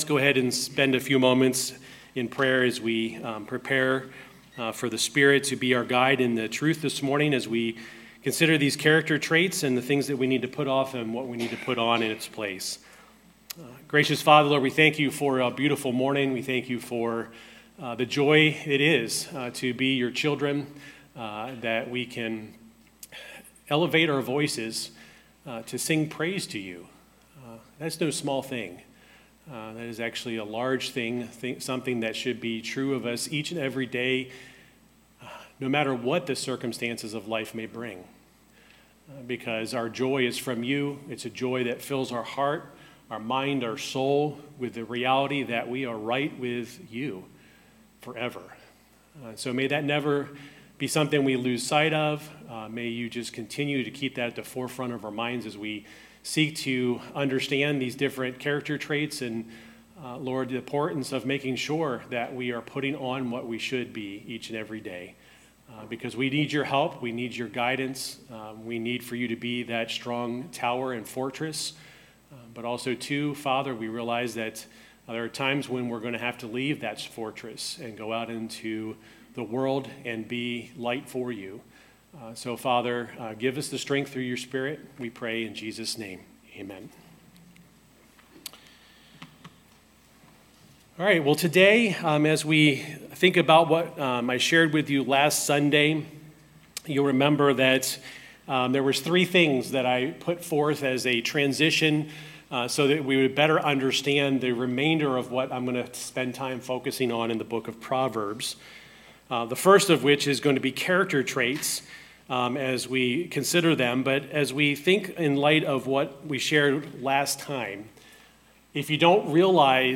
Sermons | Ellington Baptist Church